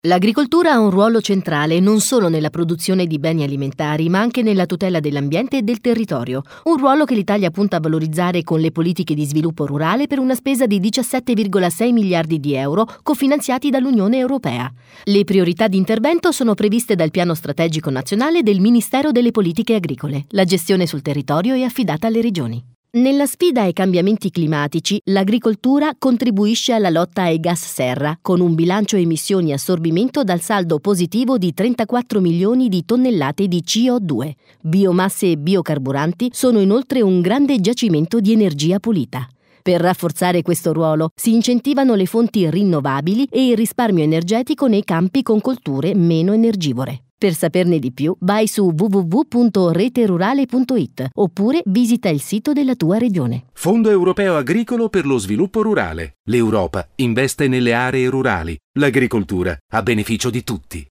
Messaggi radiofonici